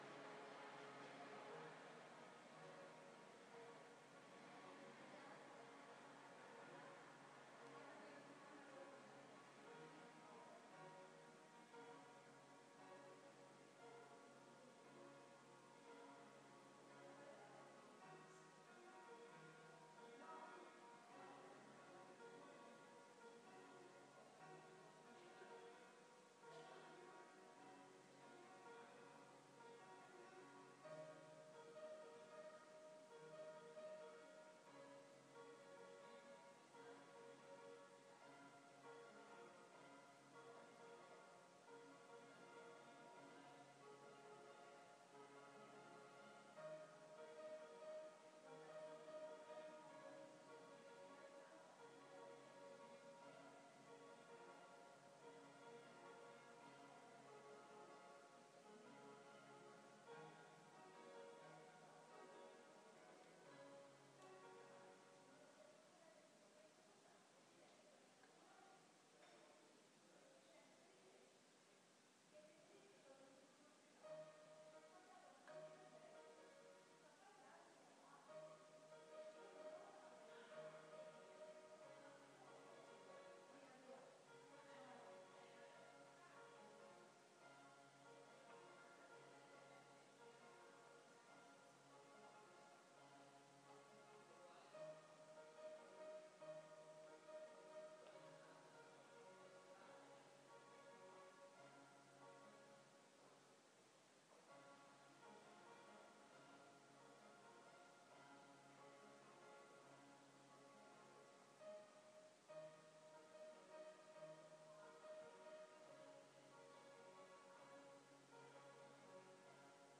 The sound of 2 minutes of silence in Piazza Annunziata in Barga Tuscany
Another two minutes of “silence” recorded on the same day in Piazza Annunziata with the sound of people walking past in the piazza and if you listen carefully, the sound floating out of the window of somebody playing a badly out of tune piano in the Palazzo Mordini.